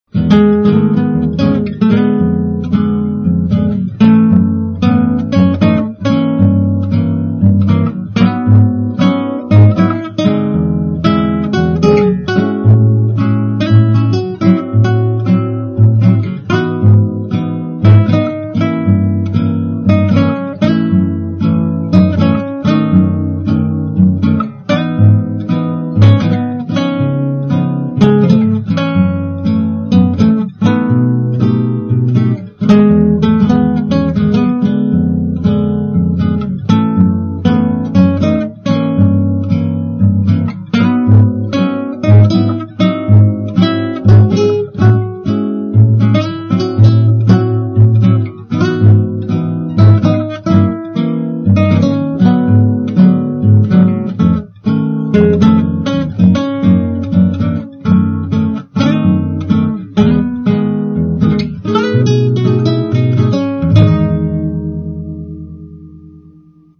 SPAZIO BOSSANOVA
accordatura chitarra
Per verificare le diteggiature, cliccate sul nome del relativo accordo: F7+ | F#° | G-7 | G#° | A-7 | F7/5+ | A#7+ | A#-6 | F7+/A | G-6 | D7/9 | C7/5+ | A7/5+ | A-7/5- | F#7/5+ | G13 | G7/5+ | | C13.